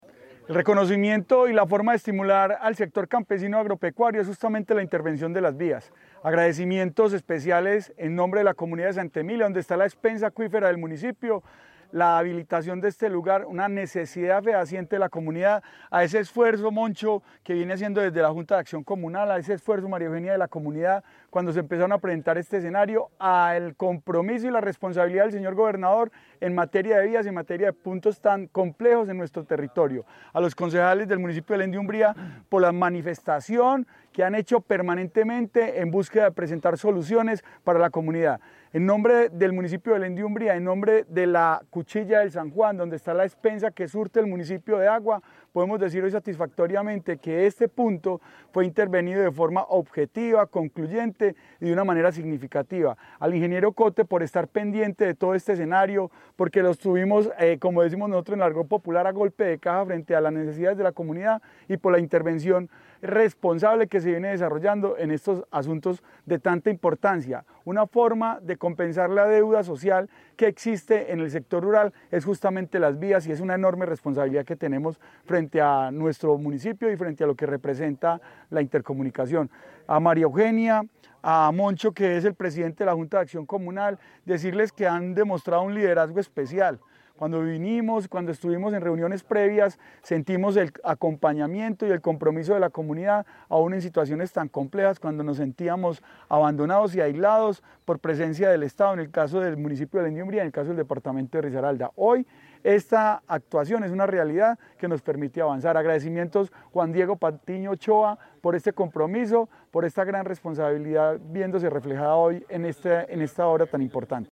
FREDY-MONTES-ALCALDE-DE-BELEN-DE-UMBRIA.mp3